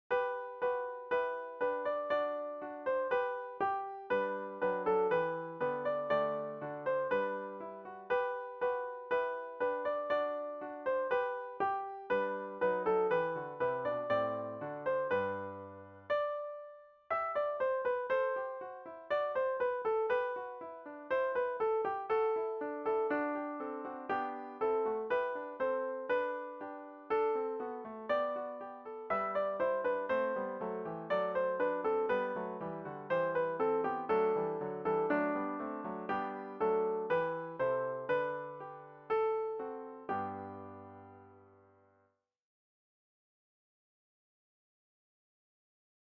Fichiers pour répéter :
Les anges dans nos campagnes soprano